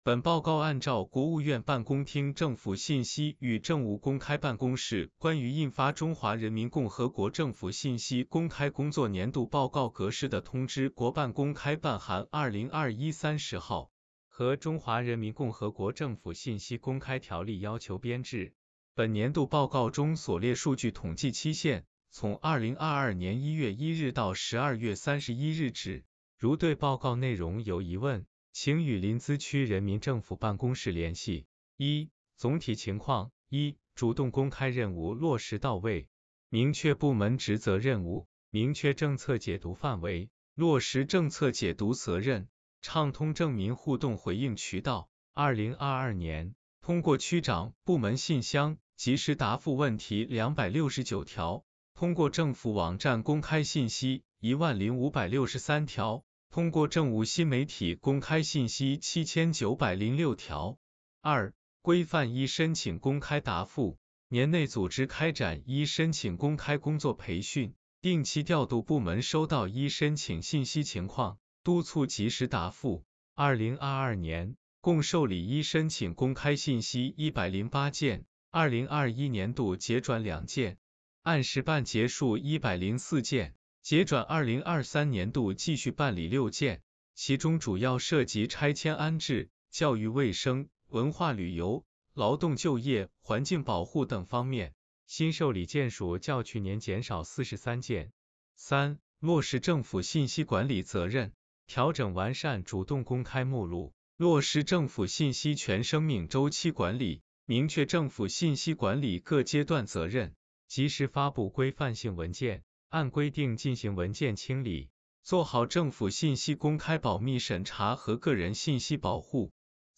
有声朗读：淄博市临淄区人民政府2022年政府信息公开工作年度报告.mp3